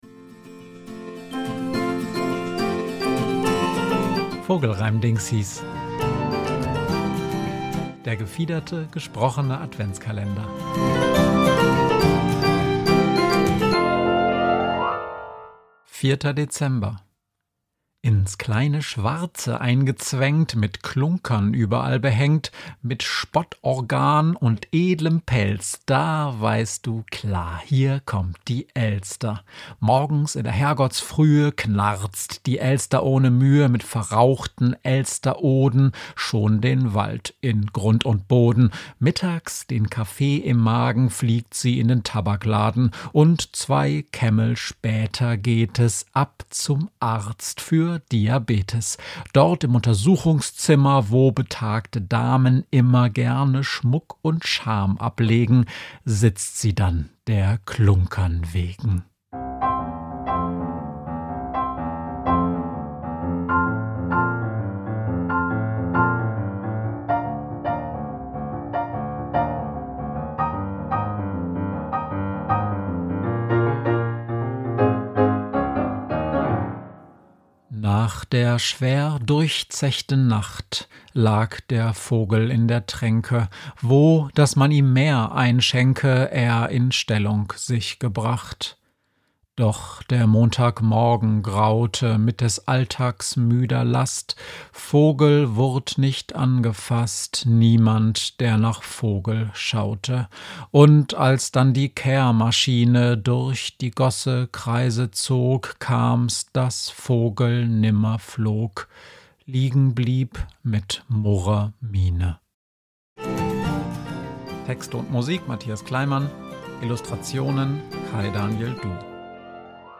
Vogelreimdingsis ist der gefiederte, gesprochene Adventskalender